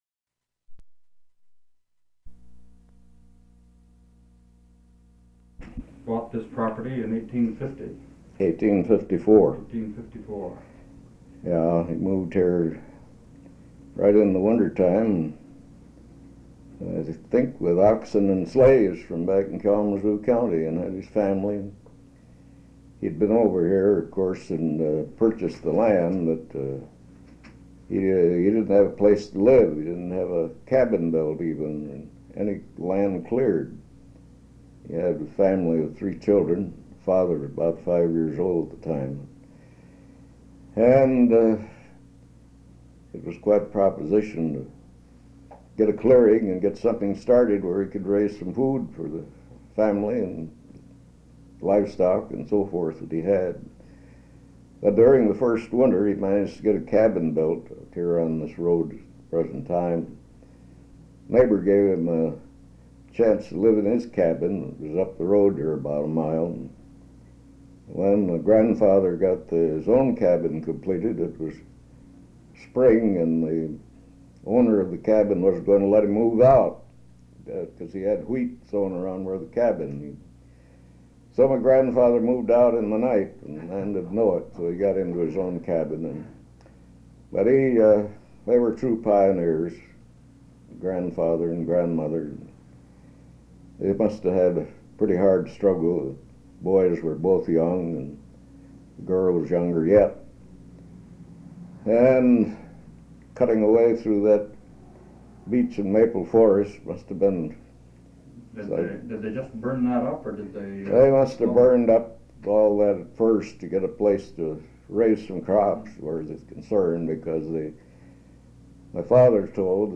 Interview
Original Format: Audio cassette tape